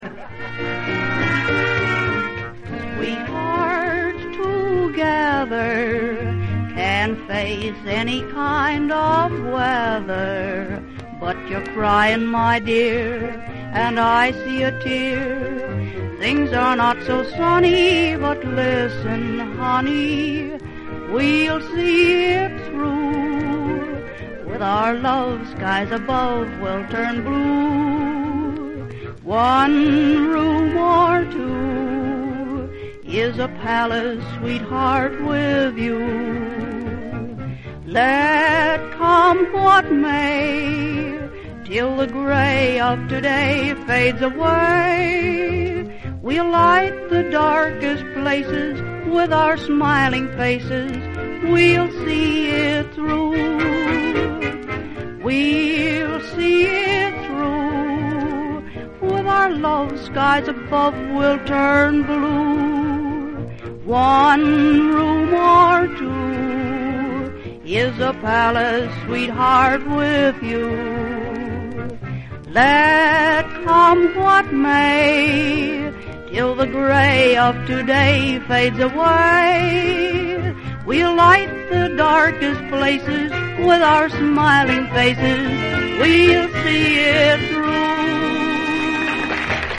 A spirit-boosting song from 1933